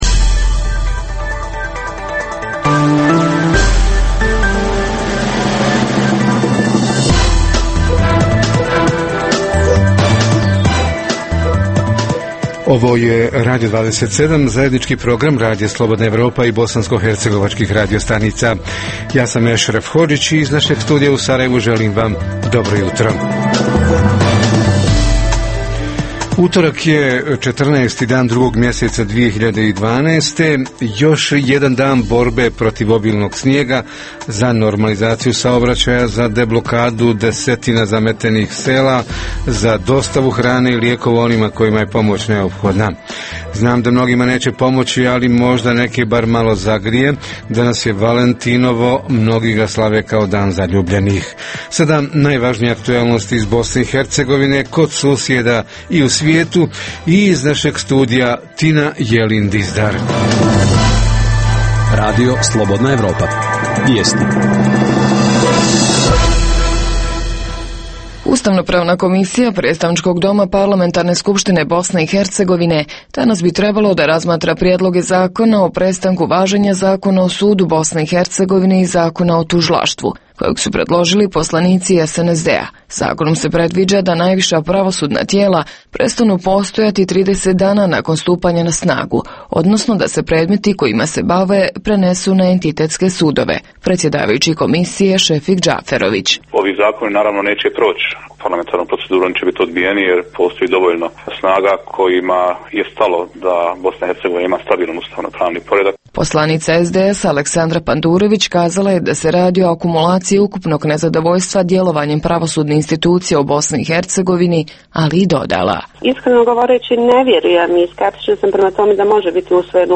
Tema jutarnjeg programa: raseljeni i povratnici – kako žive, šta rade, ko im, kako i koliko pomaže u aktuelnim zimskim uvjetima? Reporteri iz cijele BiH javljaju o najaktuelnijim događajima u njihovim sredinama.
Redovni sadržaji jutarnjeg programa za BiH su i vijesti i muzika.